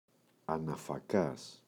αναφακάς, ο [anafa’kas]